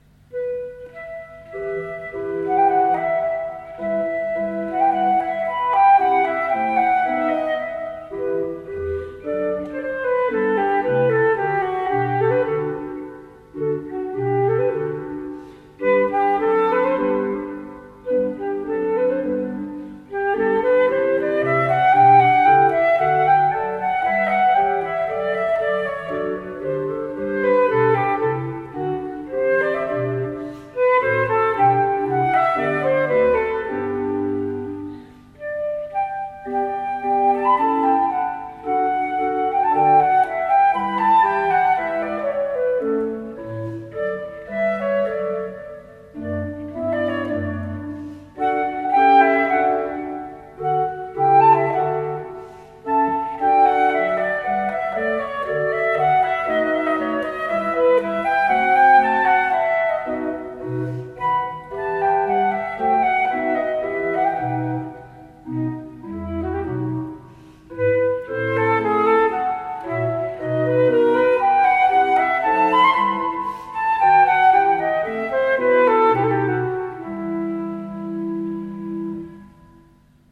FLÖTISTIN